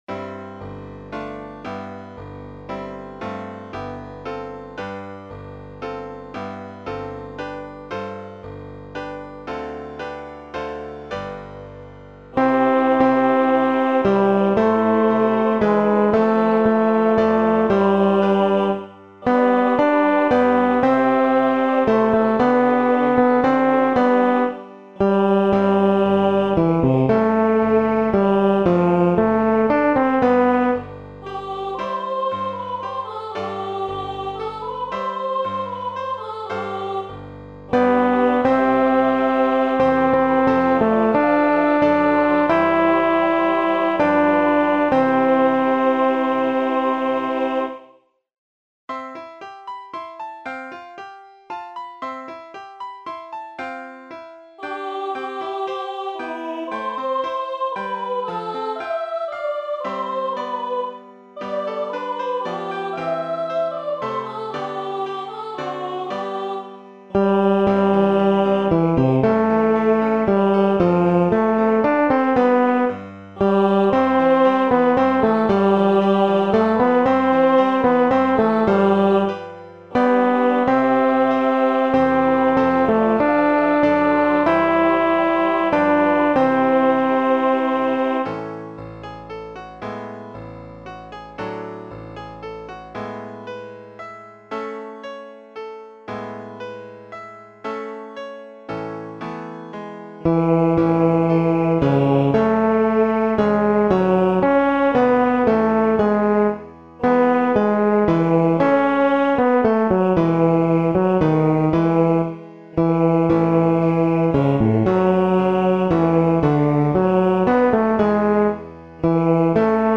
テノール（フレットレスバス音）